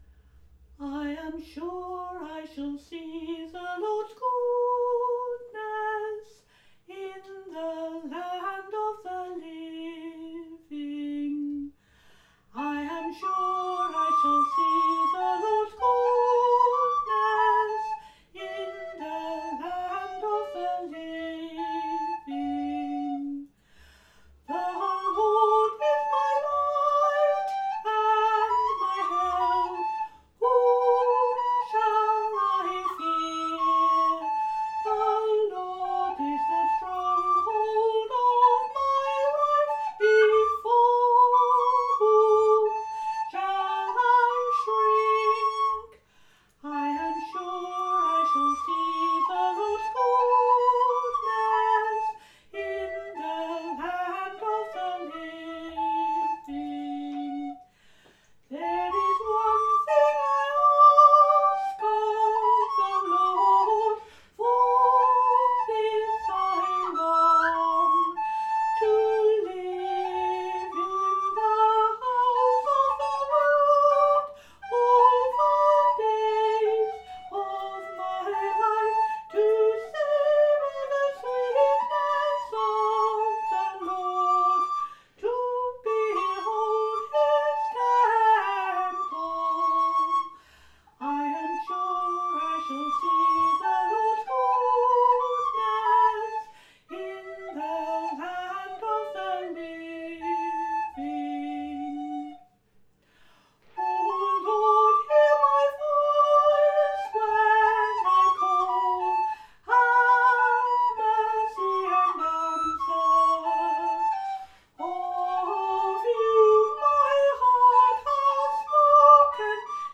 playing the recorder